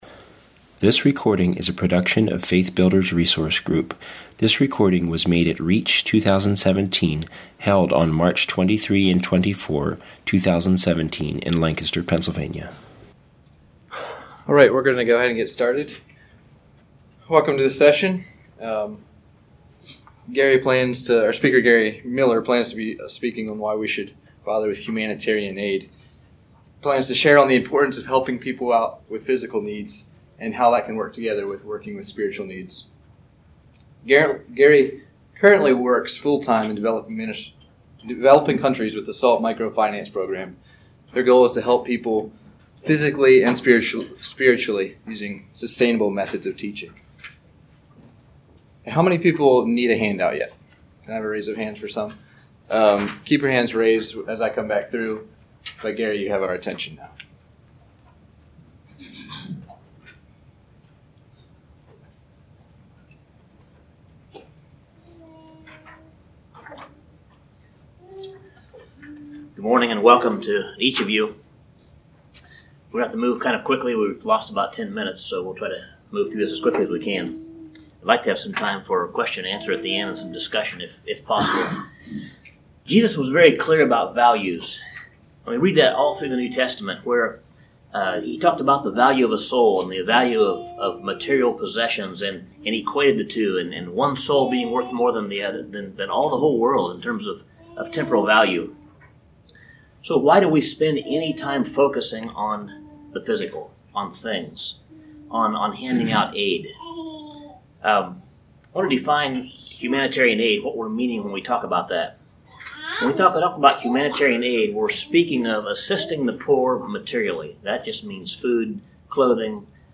And if we do decide to separate the temporal from the eternal, what is the long-term result of a mission focusing solely on either spiritual or physical need? This workshop will explore the importance of integrating the spiritual and the physical, and how this can be accomplished in our missions.